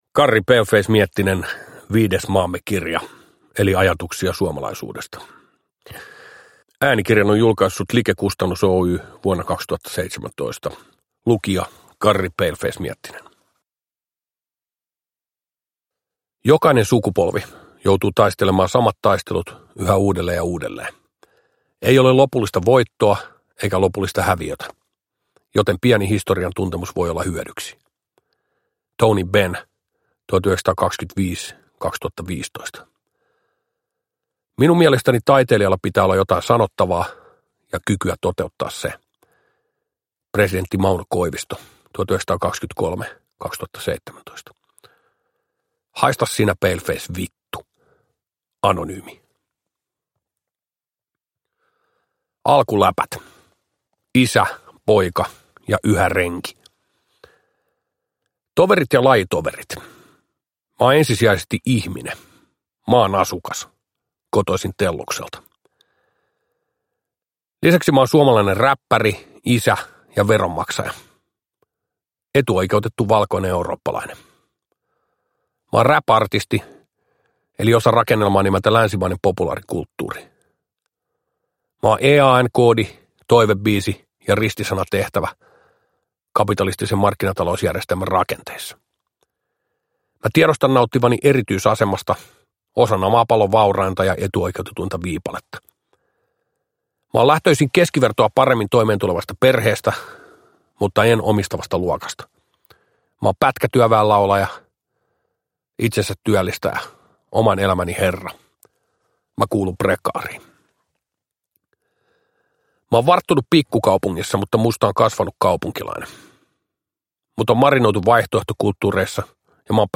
Viides Maamme-kirja – Ljudbok – Laddas ner
Uppläsare: Karri Paleface Miettinen